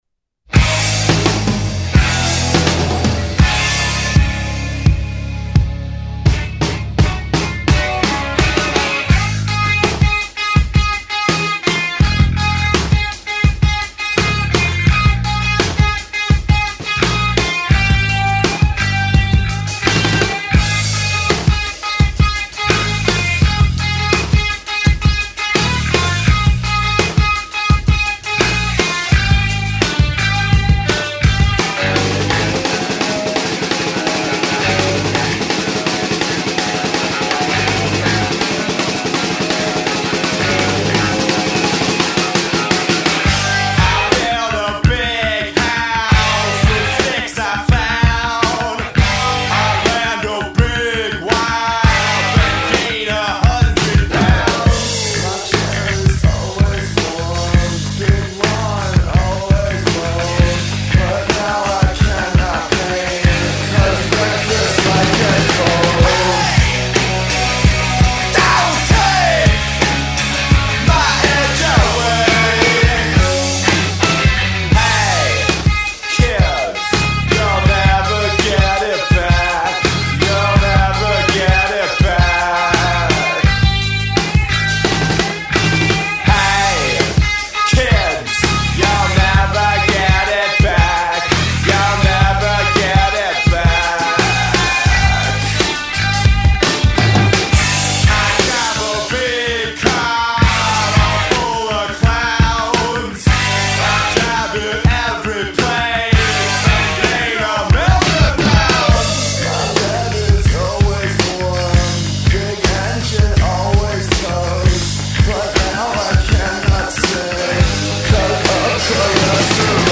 80s vintage
80’s alt rock